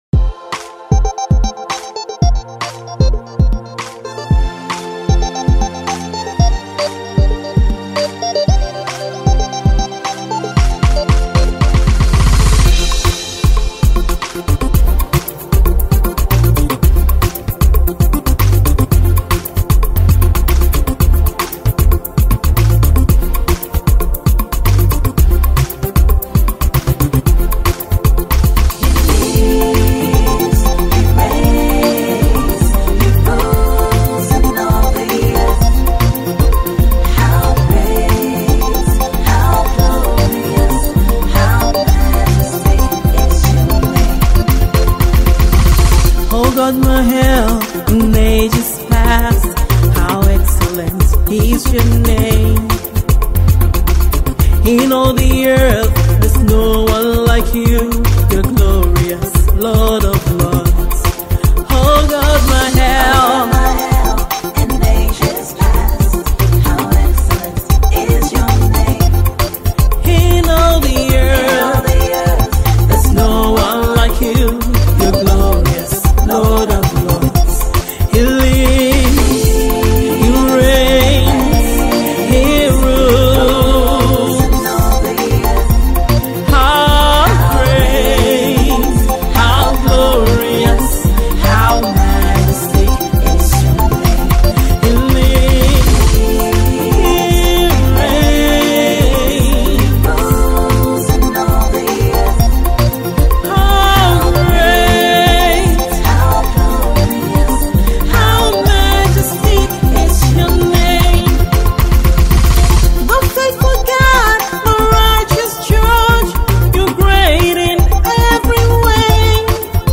Praise Song